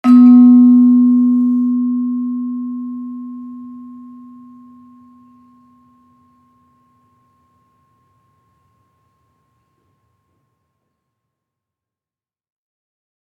Gamelan Sound Bank